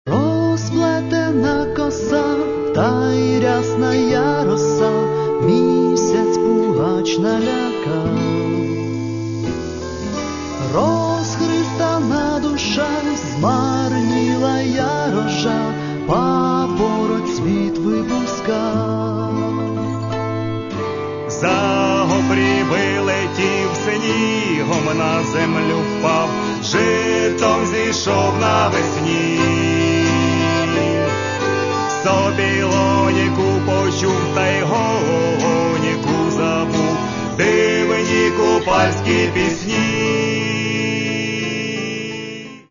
Каталог -> Народна -> Сучасні обробки